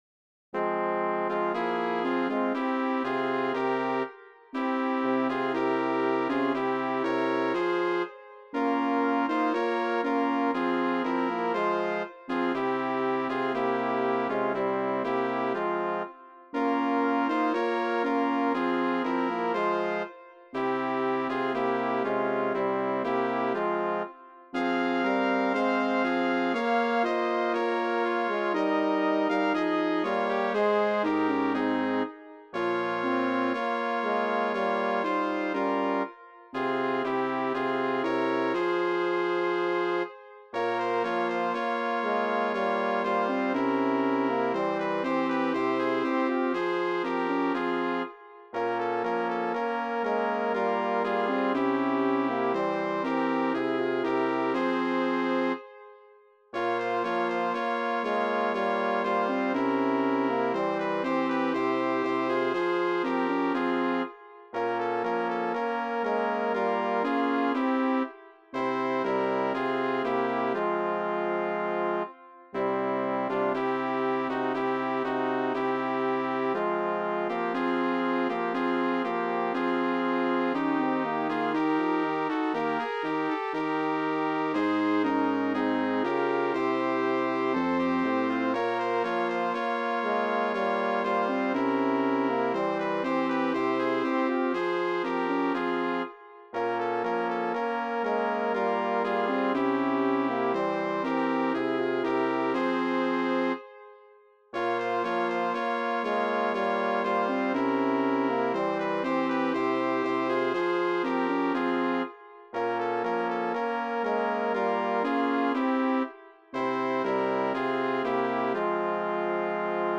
Cor mixt
midi
STROPI-DE-HAR-COBOARA-LIN-midi.mp3